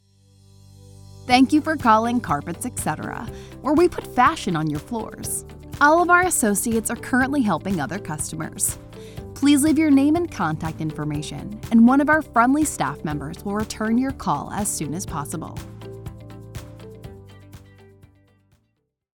Friendly Professional Greetings for Your VoIP Phone System